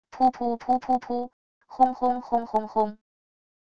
噗噗噗噗噗……轰轰轰轰轰……wav音频